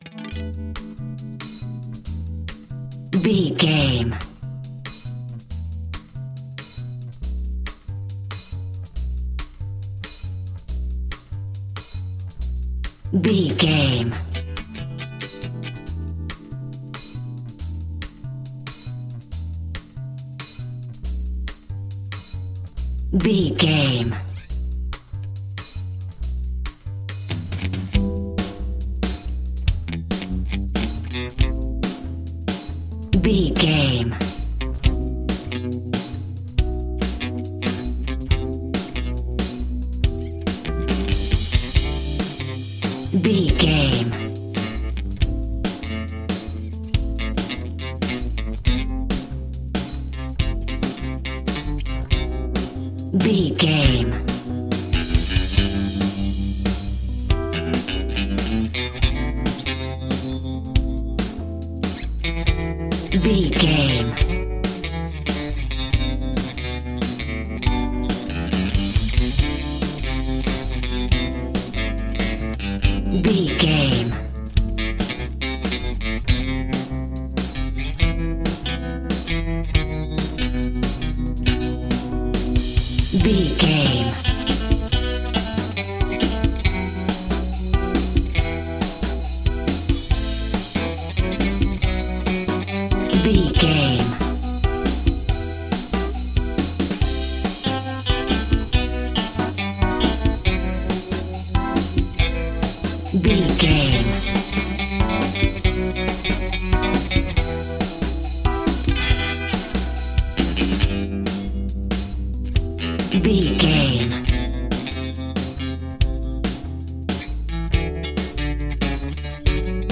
Aeolian/Minor
Fast
maracas
percussion spanish guitar
latin guitar